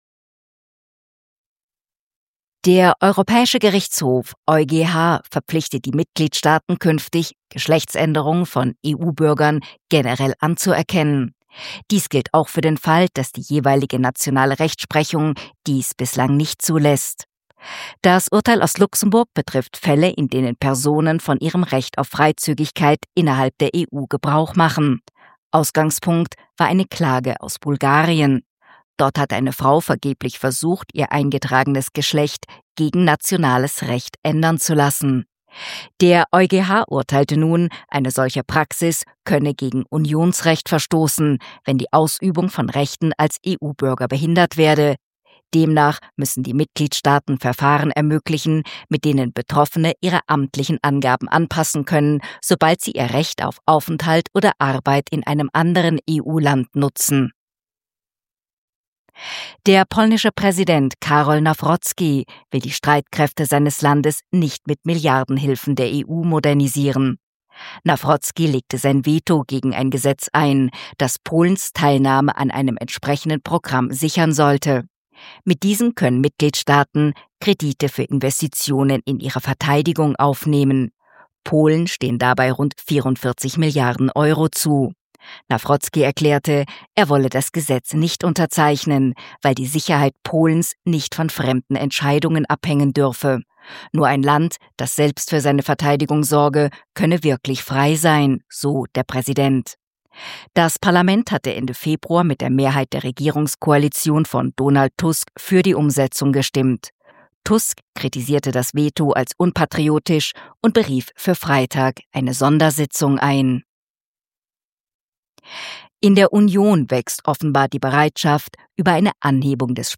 Kontrafunk aktuell 13.3.2026 – Nachrichten vom  13.3.2026